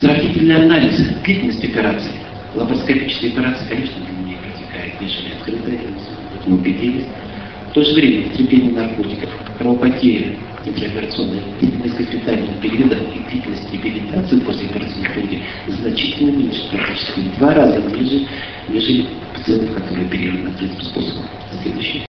5 Российская Школа по эндоскопической и открытой урологии, 8-10 декабря 2004 года.
Лекция: ЛАПАРОСКОПИЯ В УРОЛОГИИ СЕГОДНЯ.